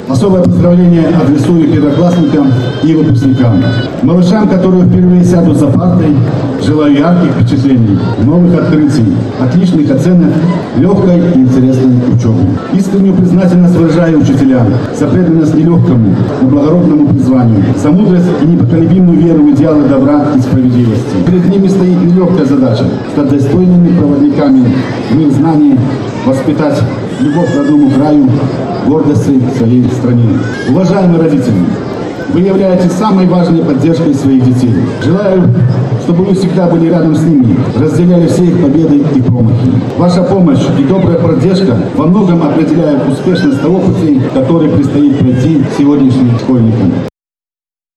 Торжественные линейки для школьников прозвучали сегодня во всех учреждениях образования Барановичей.
Глава депутатского корпуса города  Виктор Колосовский посетил гимназию №3. Председатель горсовета депутатов подчеркнул важность профессии учителя – человека, который воспитывает у молодого поколения любовь к родному краю и гордость к своей стране.